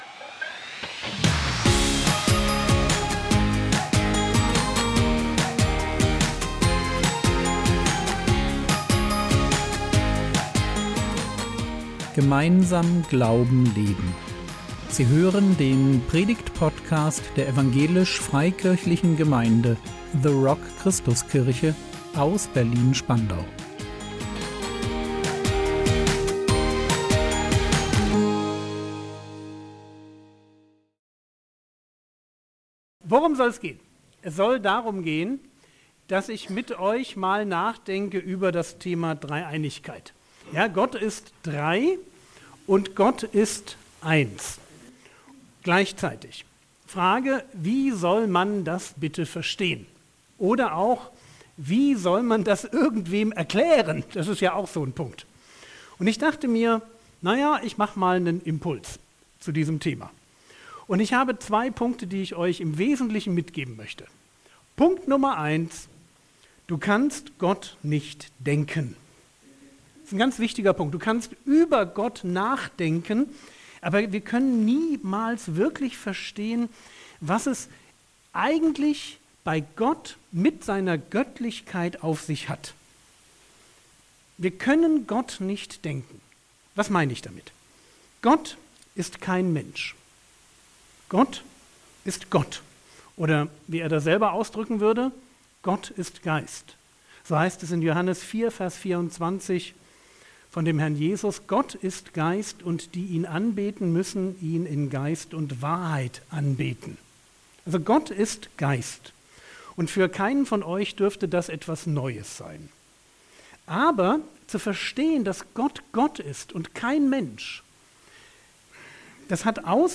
Dreieinigkeit | 12.01.2025 ~ Predigt Podcast der EFG The Rock Christuskirche Berlin Podcast
Ein Impuls